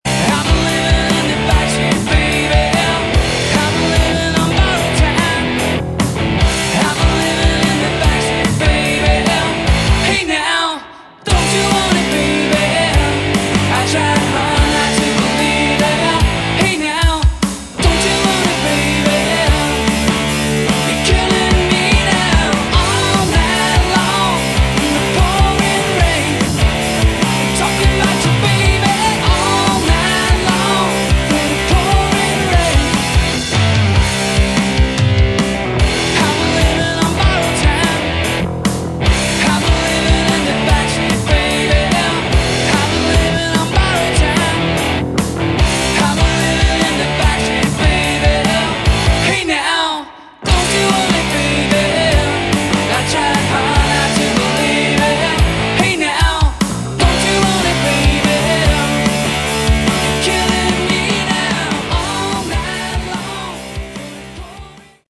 Category: Melodic Rock
Bass
Lead Vocals, Guitar
Drums
Keyboards
Summer 2007 Demo